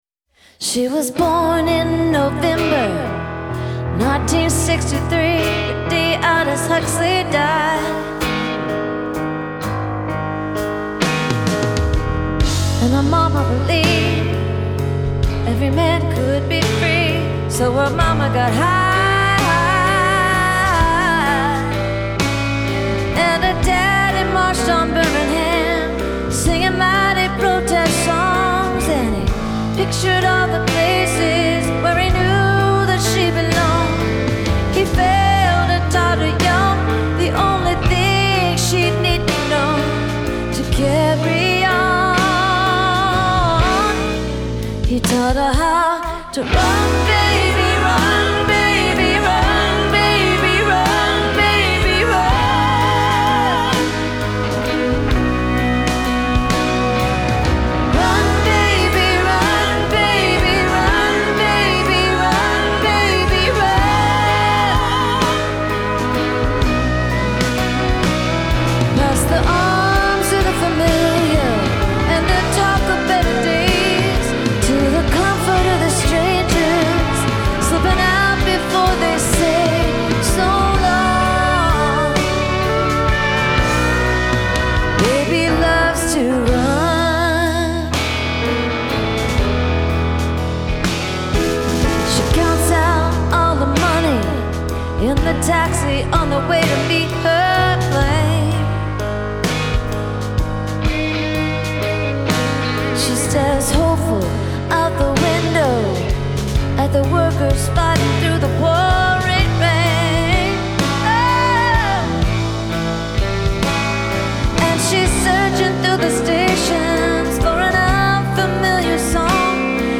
Genre : Pop
Live from the Theatre at Ace Hotel